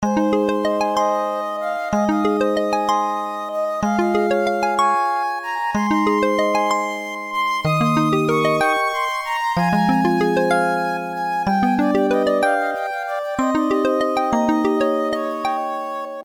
以前SH-51で作成したmmfファイルをmp3ファイルに録音し直しました。
再生する機種により、音は、多少異なって聞こえます。